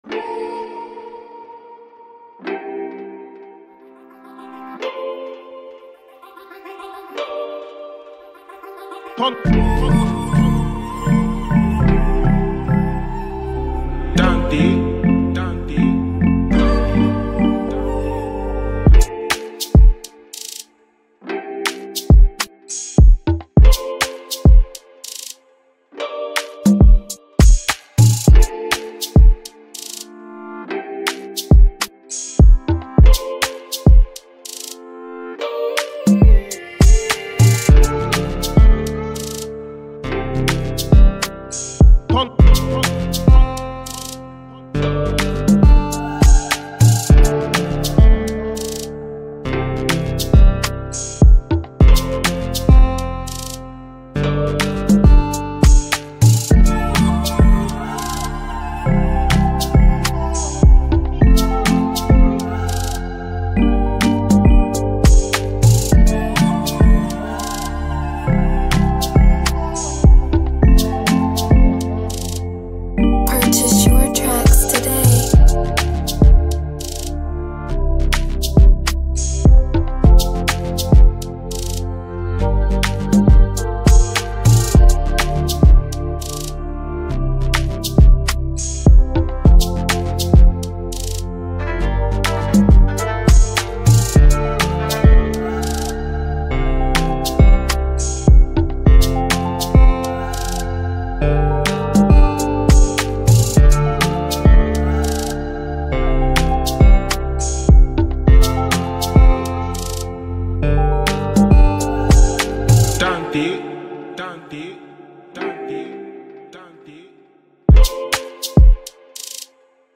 the perfect balance of energetic drums and catchy melodies